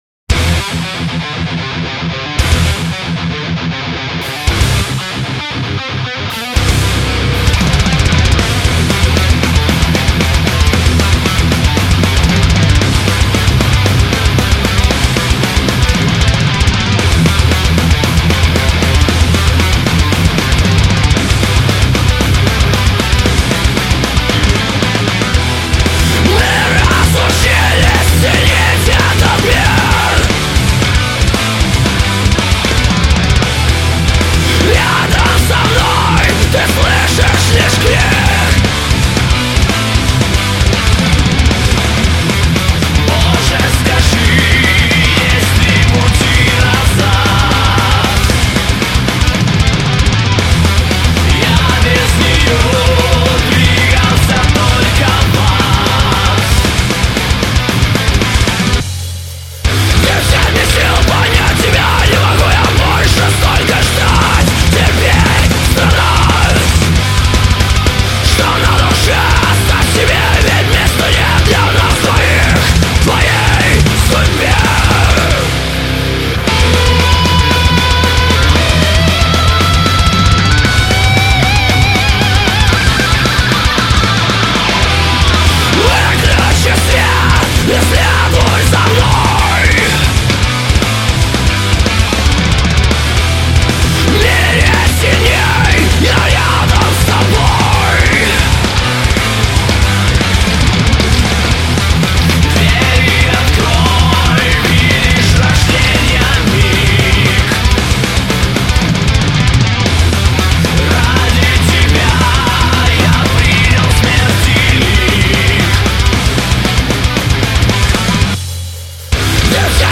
�������� Metall Core ����� 2007-2009 ����!)